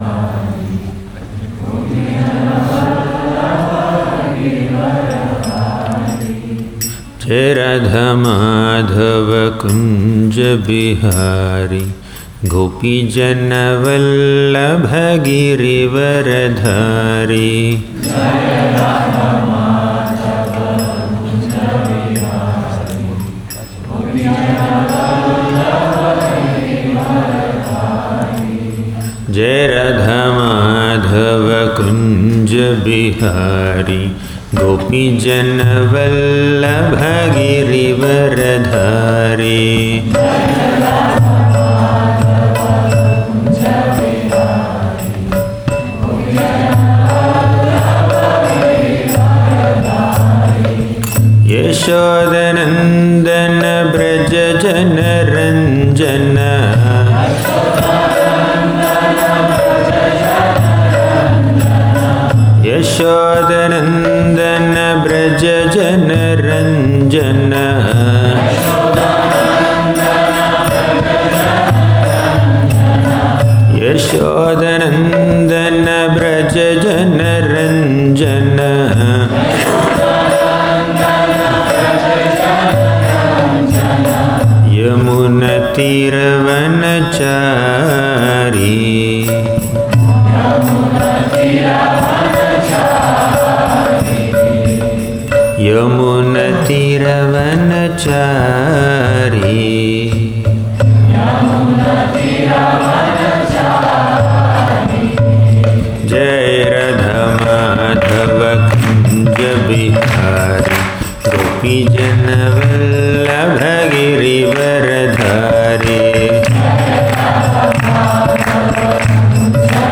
Govardhana Retreat Center